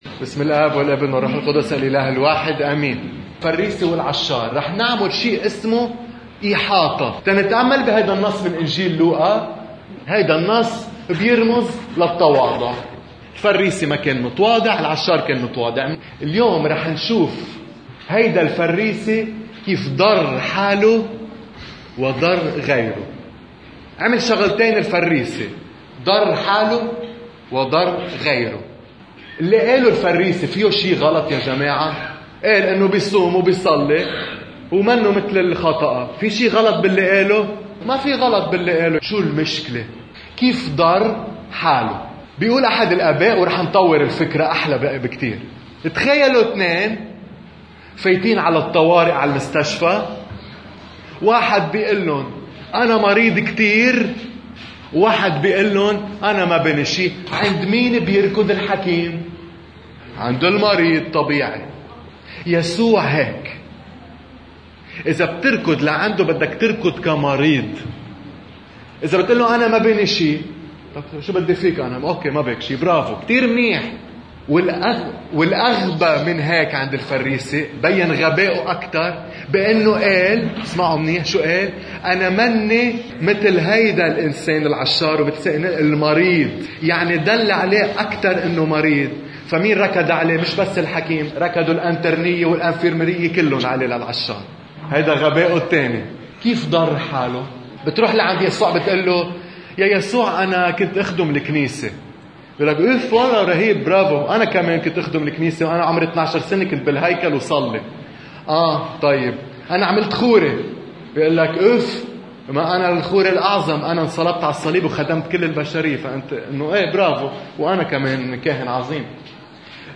عظة أحد الآباء حول تفسير مثل الفريسيّ العشار: